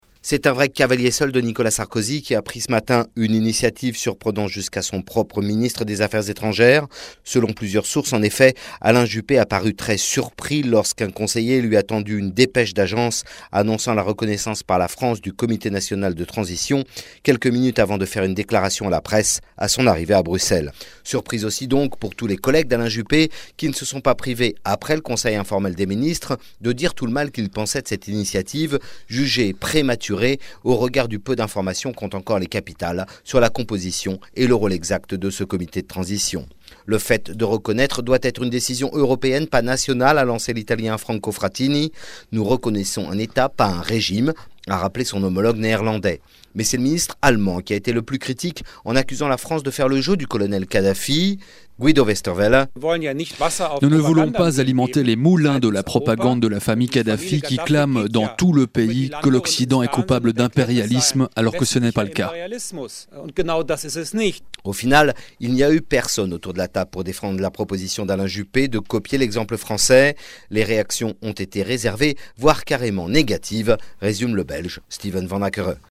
journaliste à Bruxelles